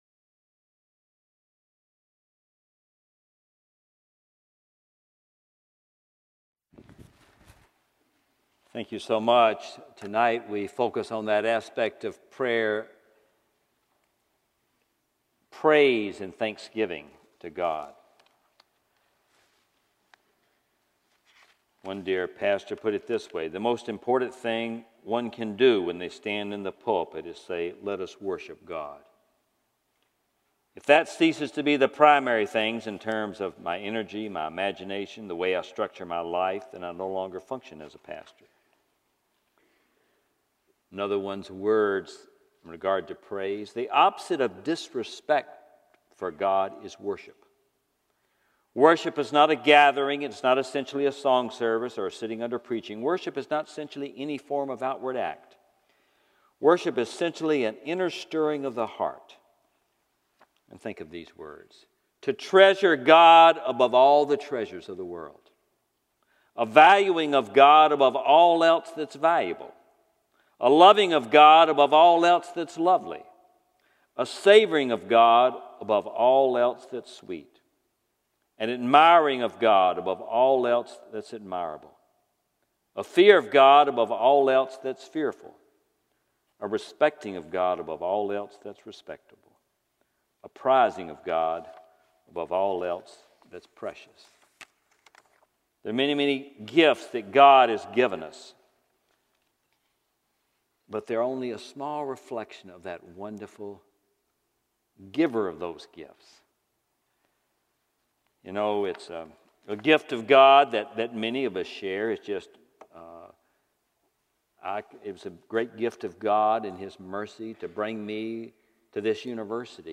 Service Type: Special Event